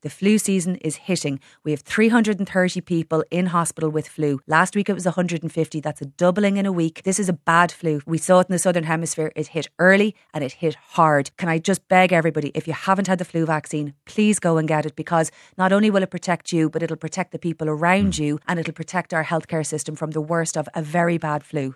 Minister Jennifer Carroll MacNeill says this year’s flu strain is already taking its toll on the health service…………